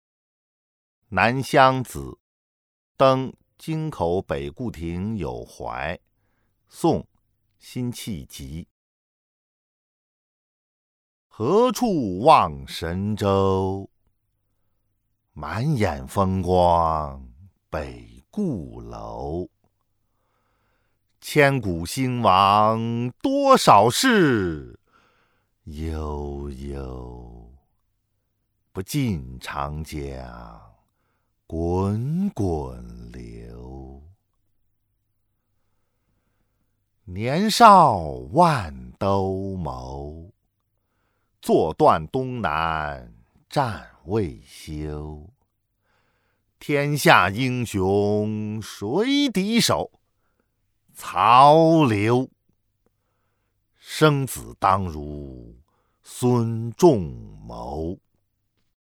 【宋】辛弃疾 《南乡子·登京口北固亭有怀》（读诵）